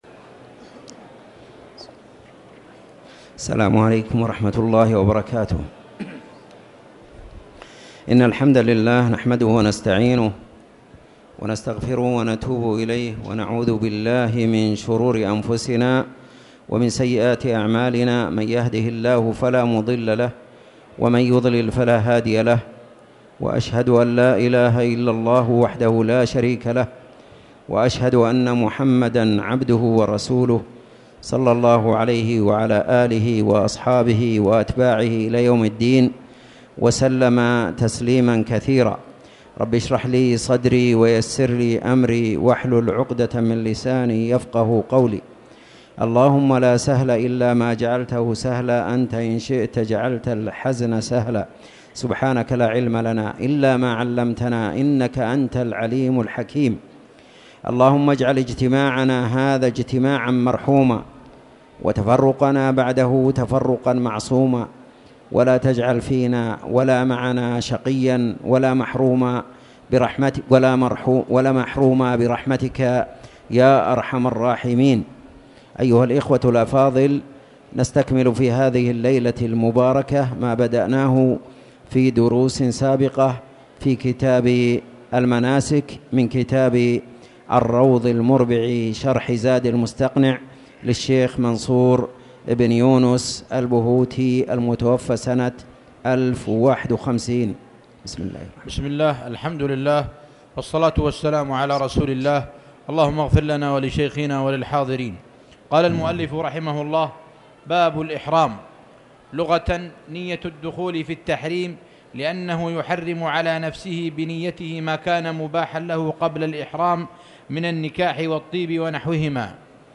تاريخ النشر ١٣ رجب ١٤٣٨ هـ المكان: المسجد الحرام الشيخ